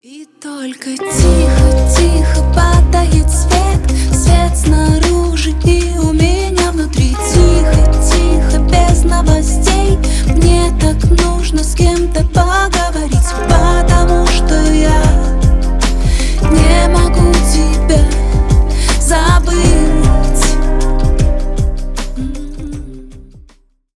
Поп Музыка
грустные # спокойные # тихие